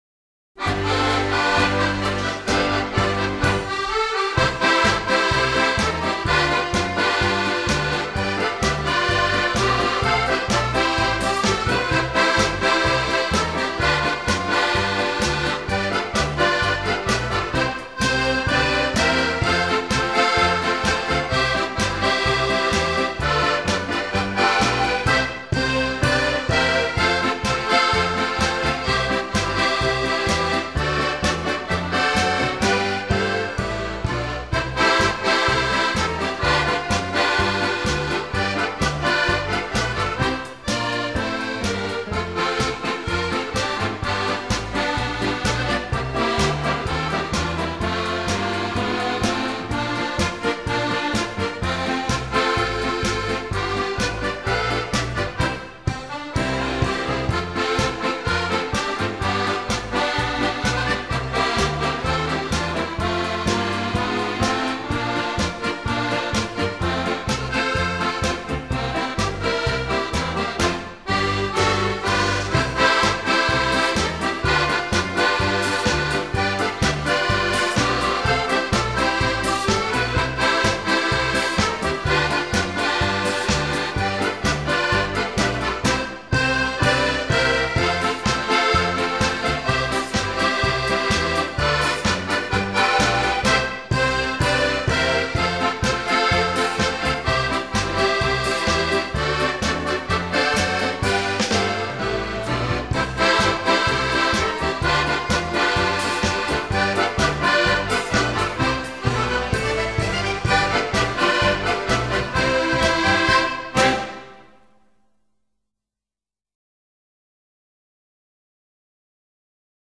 Genre: Scottish.